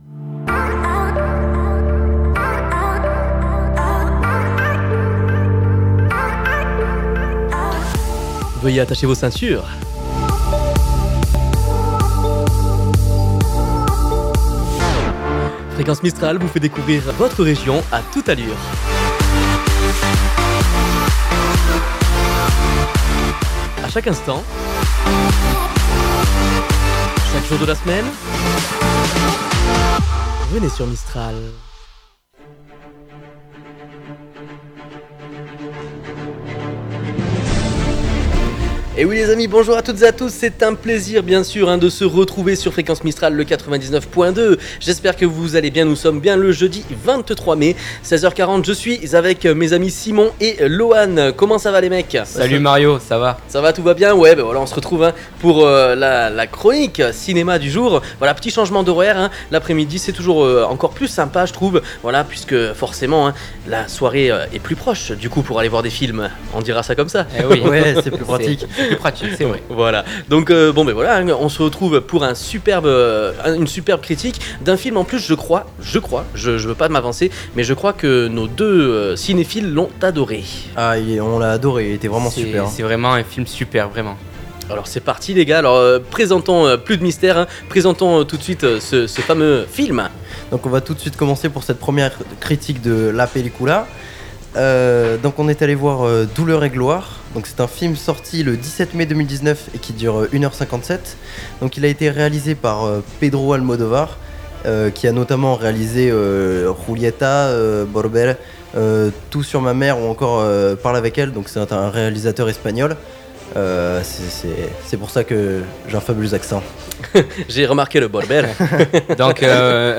notre émission spéciale critique de film